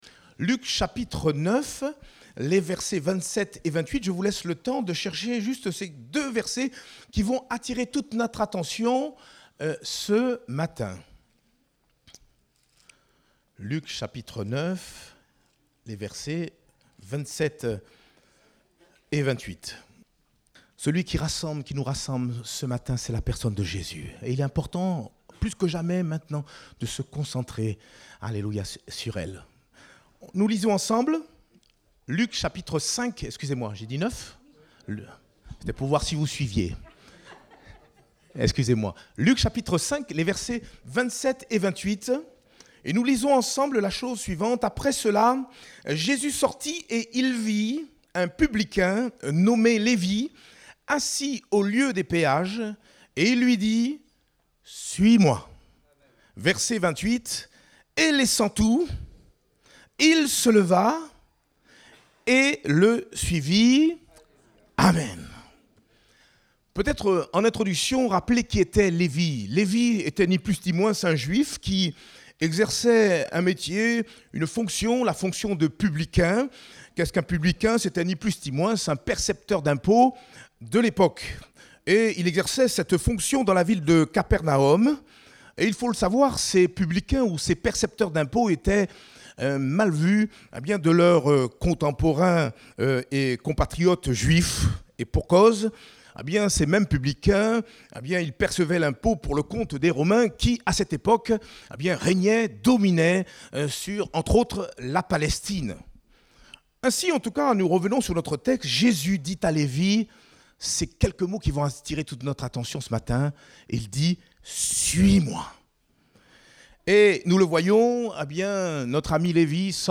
Date : 24 avril 2022 (Culte Dominical)